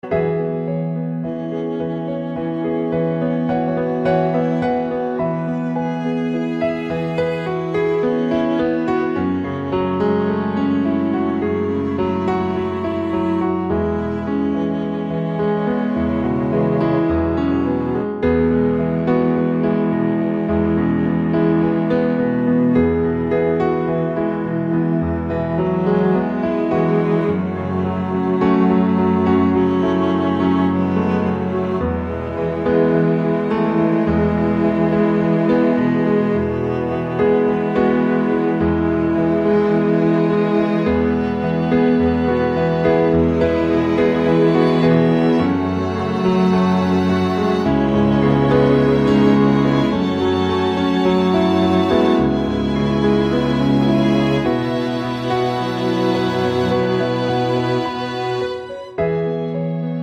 Acoustic Piano Version